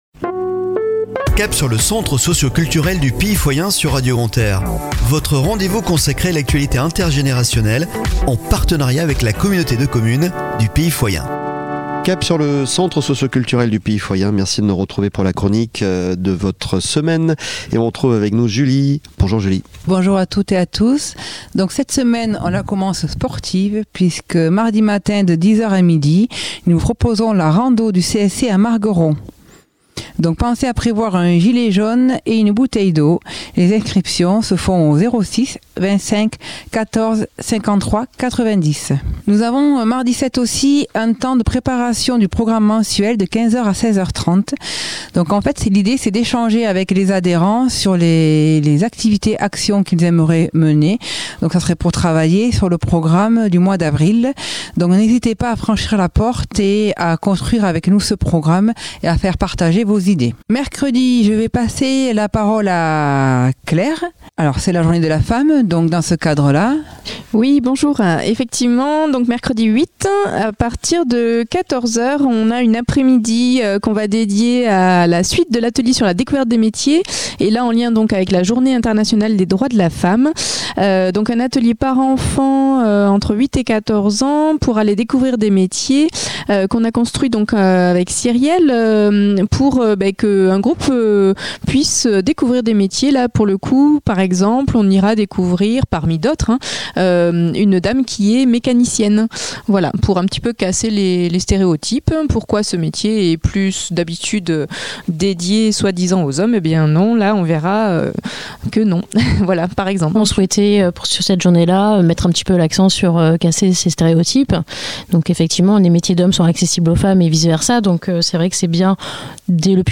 Chronique de la semaine du 06 au 12 Mars 2023 !